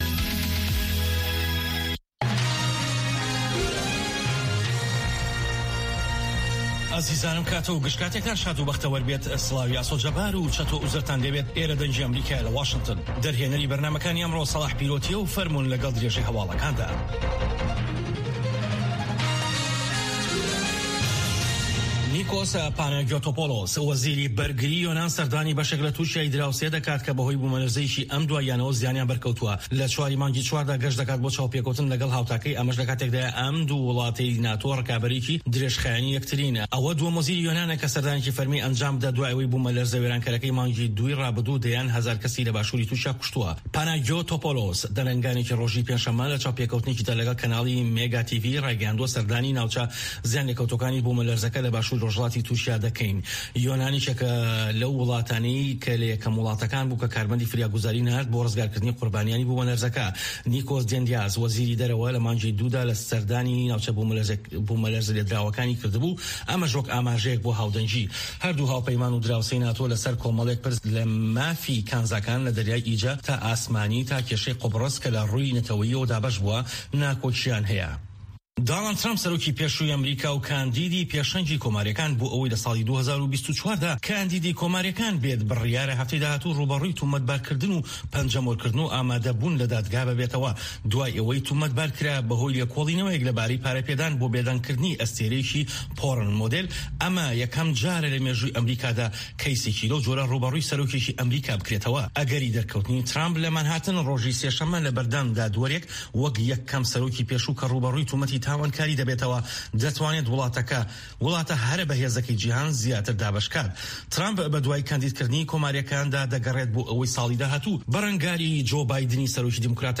هەواڵە جیهانییەکان 1
هەواڵە جیهانیـیەکان لە دەنگی ئەمەریکا